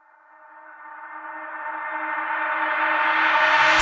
SouthSide FX (4).wav